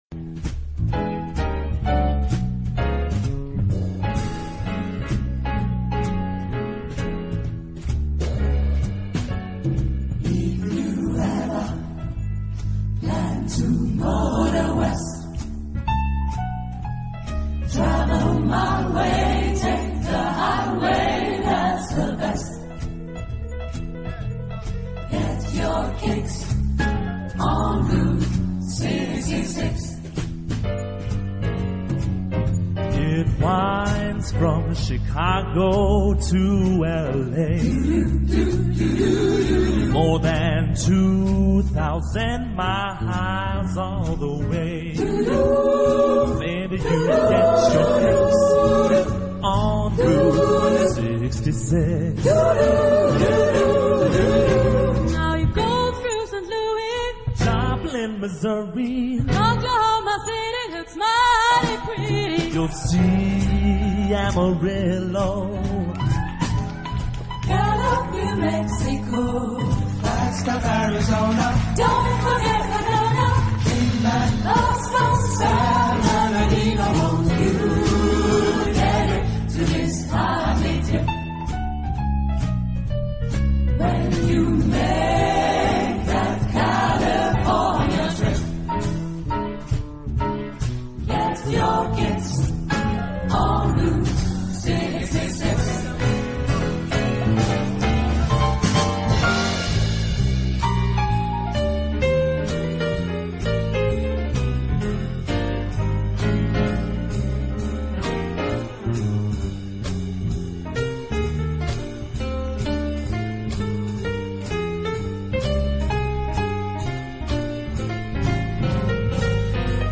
Genre-Style-Form : Choral jazz
Mood of the piece : rhythmic ; forceful
Type of choir : SSATB (5 mixed voices )
Soloists : Homme (1) / Femme (1) (2 soloists)
Instrumentation : Jazz band (3 instrumental parts)
Tonality : E major